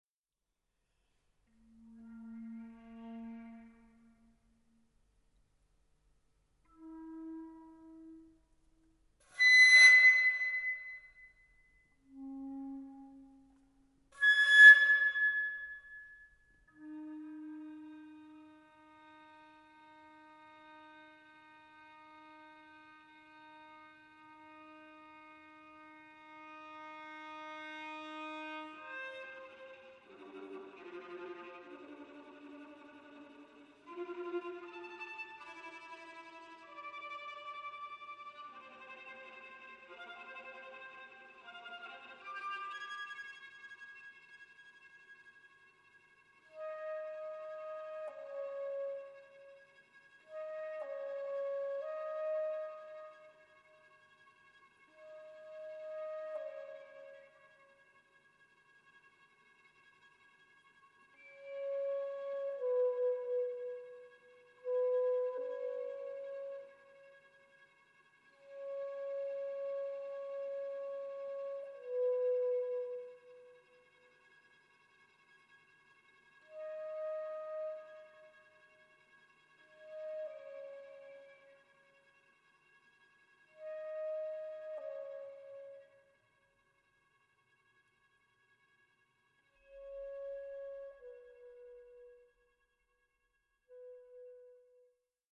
It sounds like a bell or gong heard from afar.